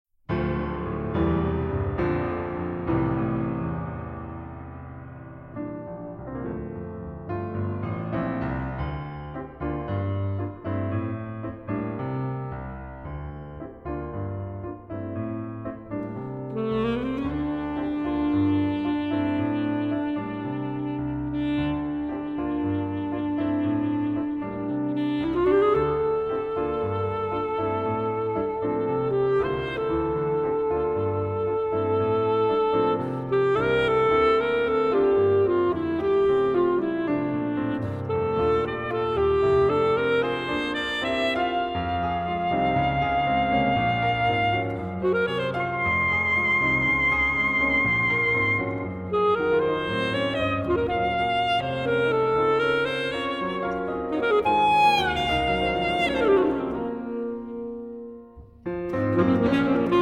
Saxophone
Piano
Sonata for Alto Saxophone and Piano